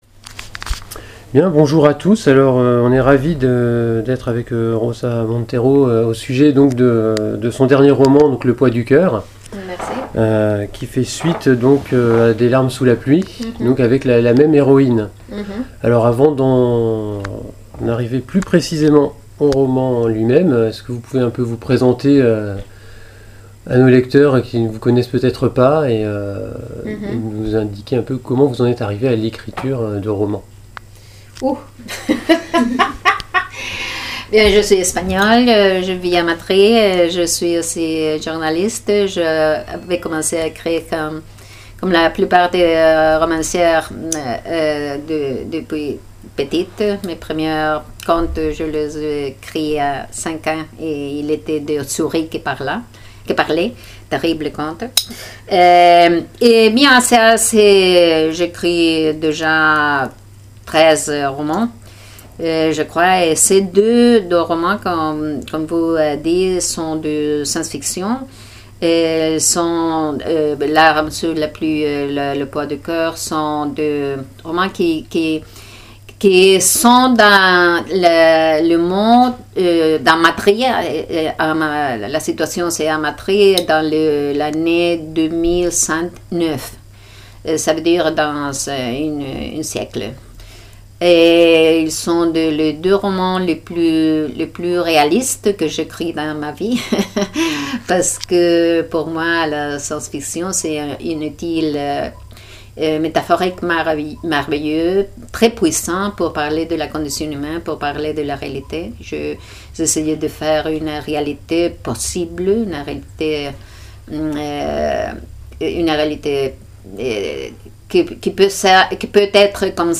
Interview de Rosa Montero pour Le poids du coeur